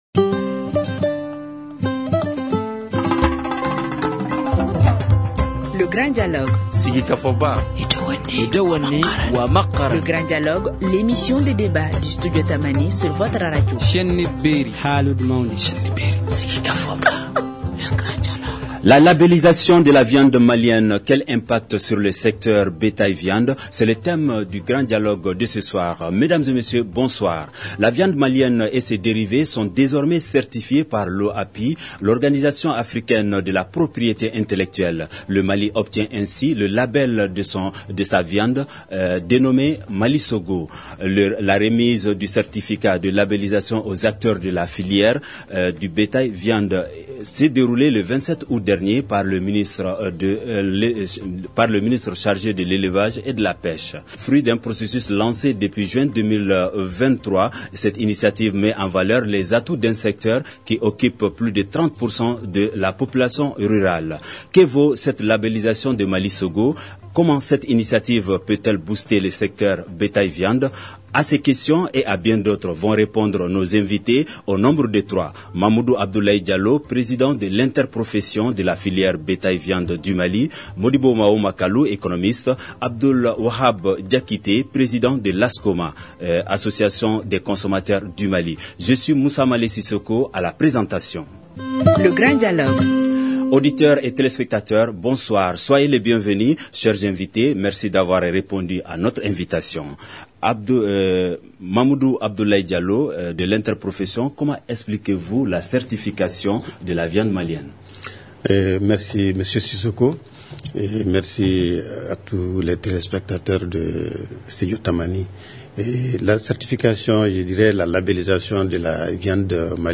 économiste